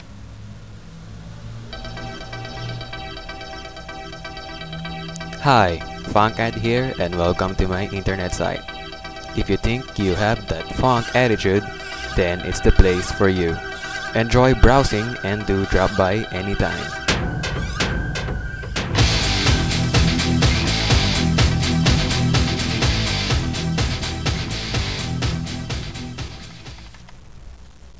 techno sample 2